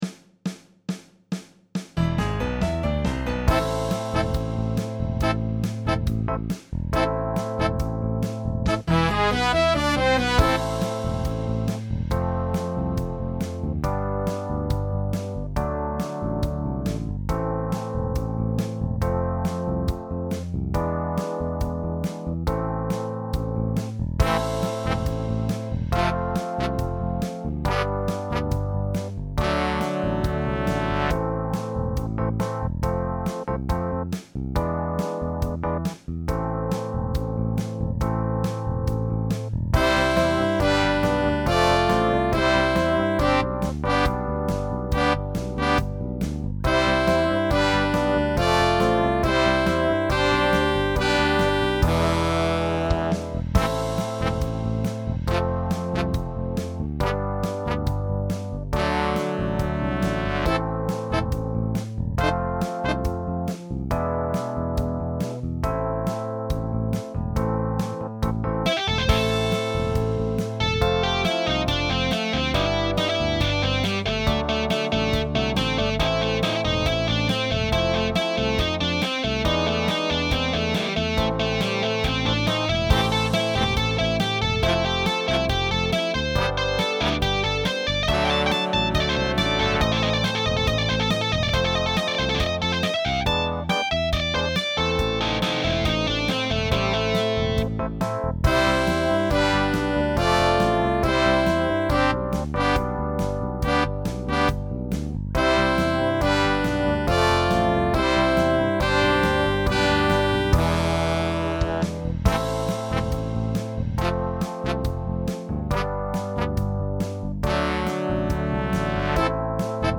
with added guitar solo